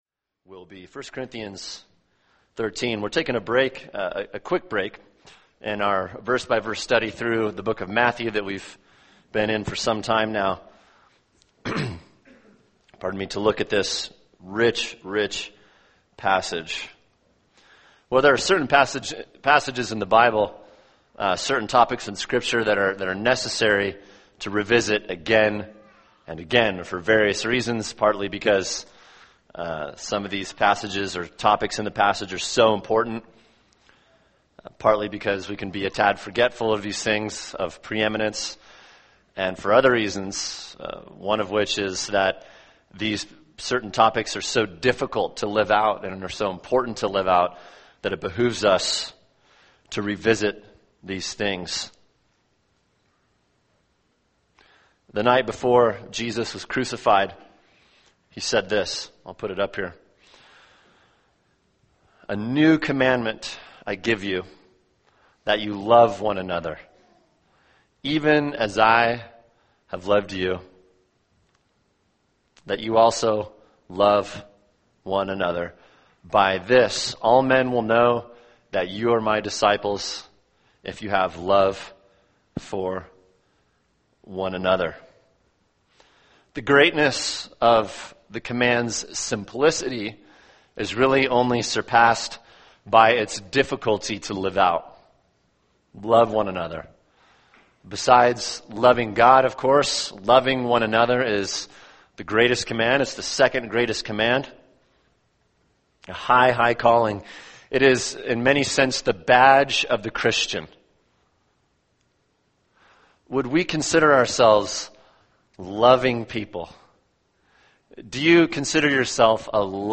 [sermon] 1 Corinthians 13:1-7 – Love (part 1) | Cornerstone Church - Jackson Hole